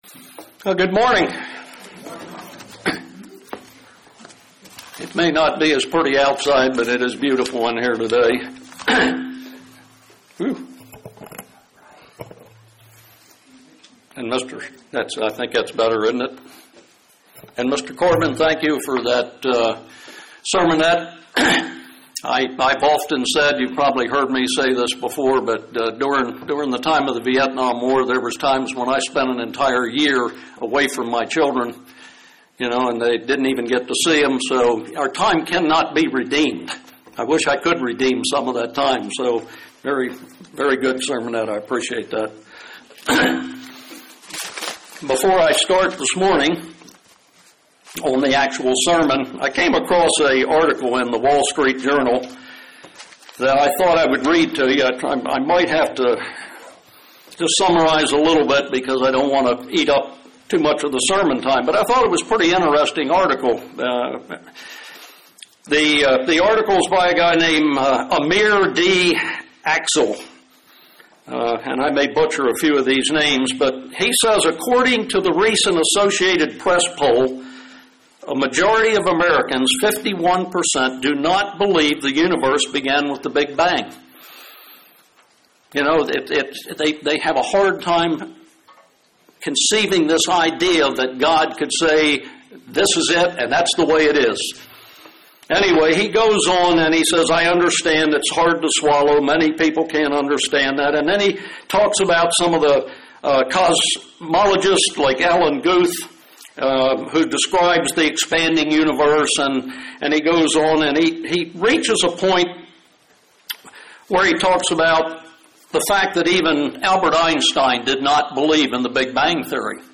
Given in Murfreesboro, TN
UCG Sermon Studying the bible?